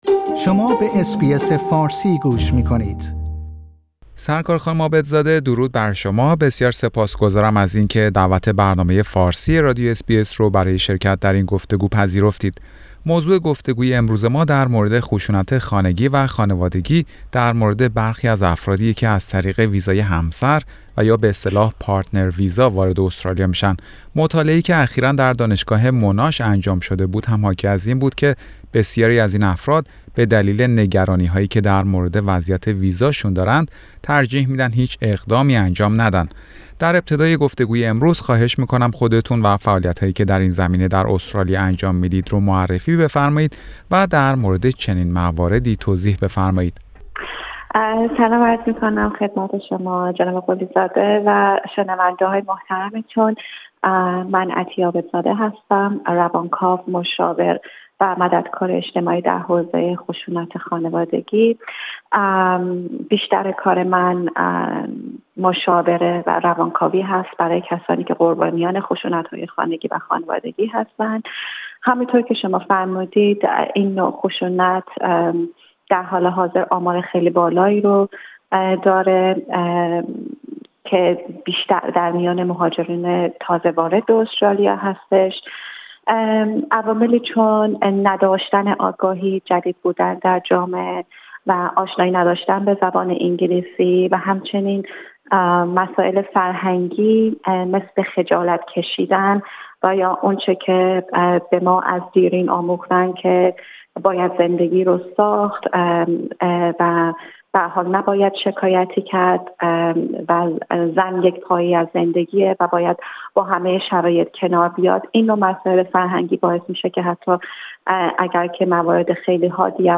برنامه فارسی رادیو اس بی اس گفتگویی داشته